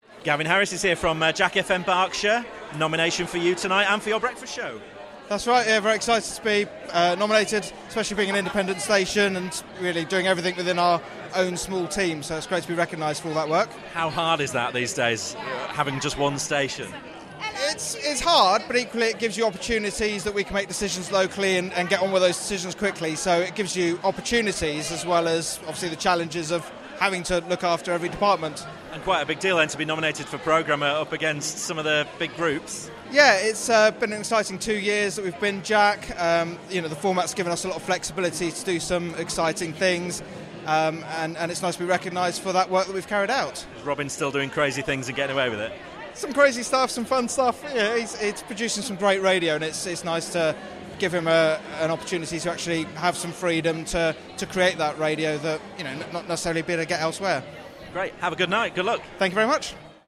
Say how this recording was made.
at the Arqiva Commercial Radio Awards 2016